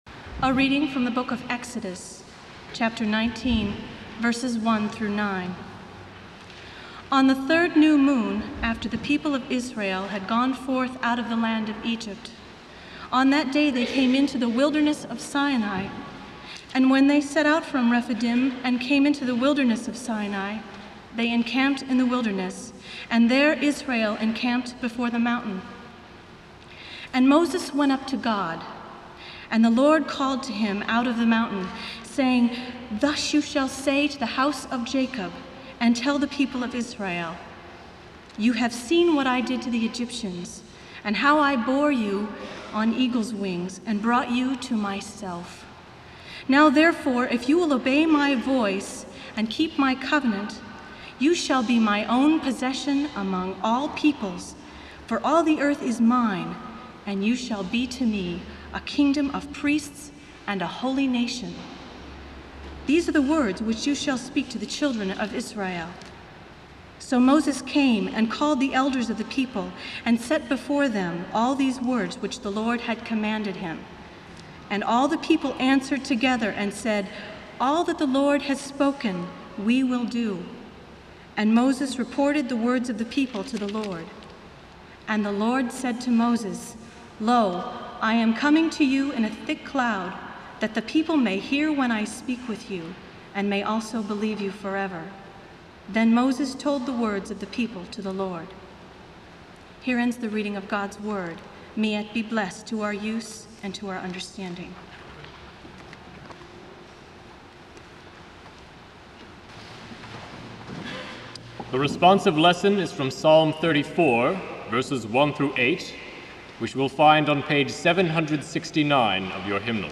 Scriptures: Exodus 19:1-9, Psalm 34:1-8, and from the Gospel of Matthew. There is a short break at 15:16 where the cassette tape was turned over.